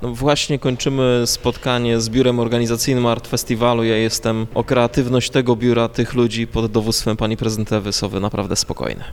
Na to pytanie Twojemu Radiu odpowiedział Rafał Zając.